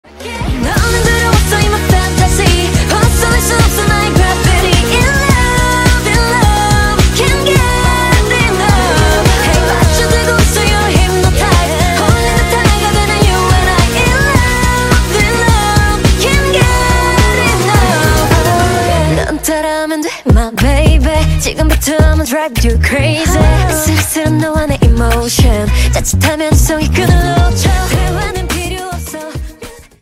KPop